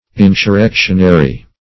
Insurrectionary \In`sur*rec"tion*a*ry\, a.